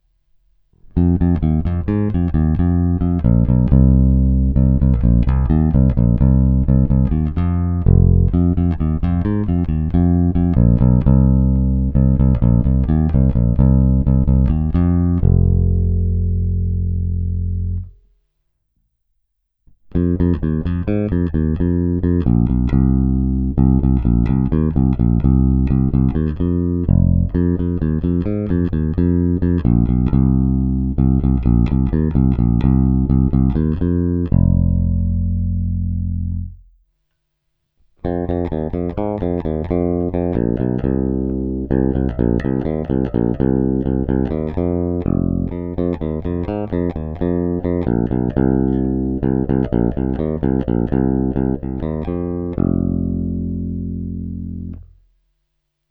Baskytara má dostatek středů umožňujících jí se prosadit v kapele a zároveň tmelit zvuk.
Není-li uvedeno jinak, následující nahrávky jsou provedeny rovnou do zvukové karty, jen normalizovány, jinak ponechány bez úprav.